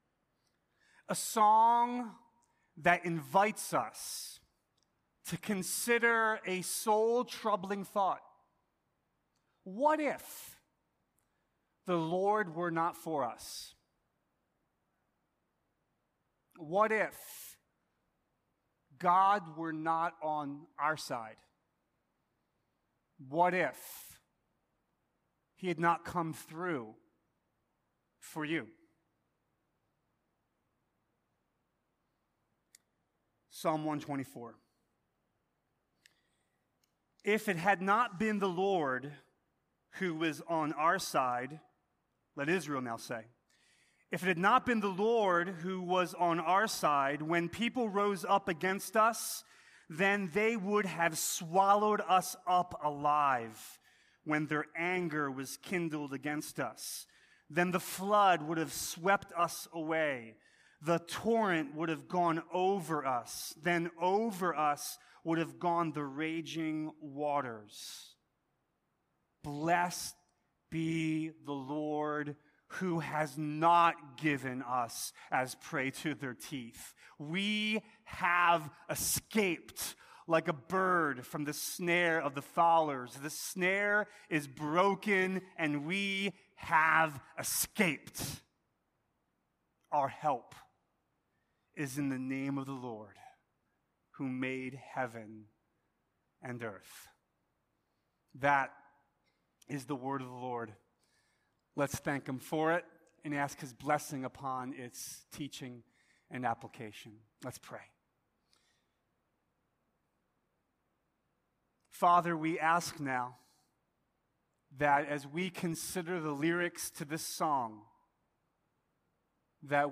A sermon from the series "The Psalms." Psalm 130 is written out of the depths, where we can find ourselves believing that we're alone.